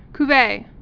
(k-vā, ky-vā)